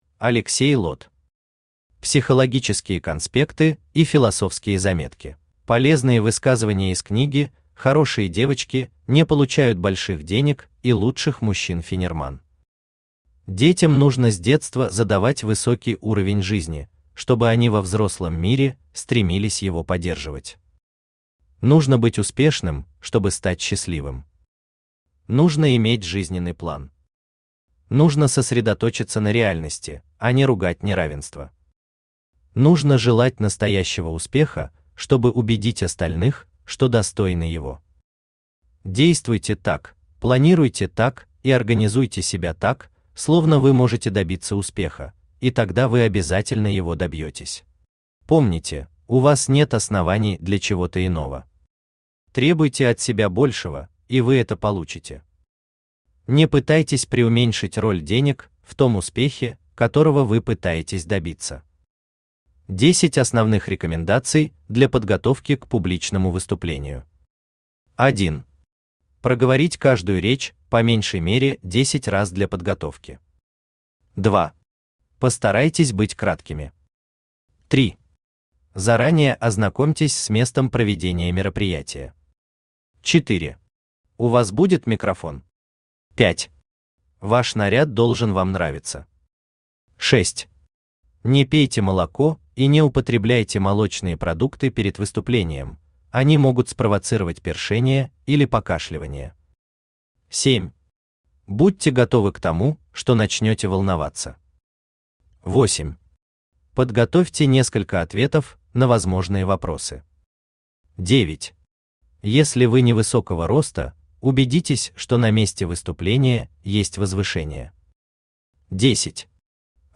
Аудиокнига Психологические конспекты и философские заметки | Библиотека аудиокниг
Aудиокнига Психологические конспекты и философские заметки Автор Алексей Сергеевич Лот Читает аудиокнигу Авточтец ЛитРес.